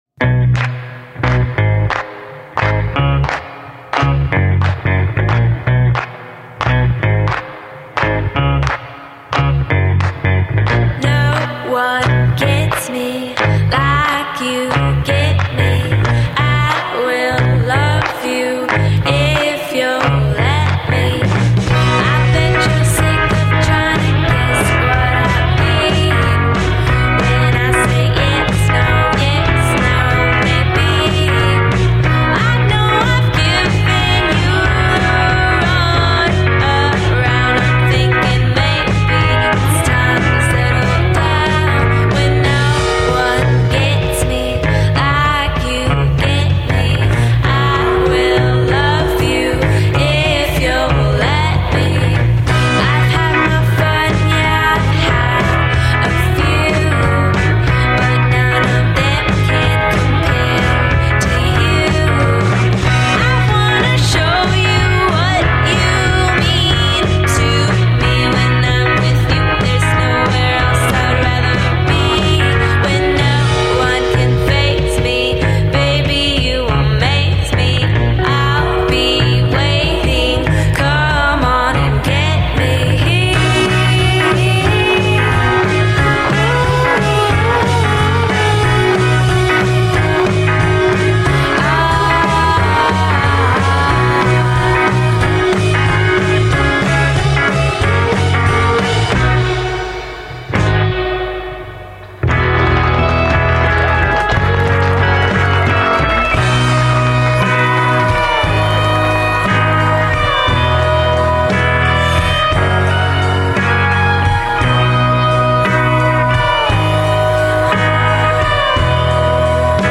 Sono due sorelline californiane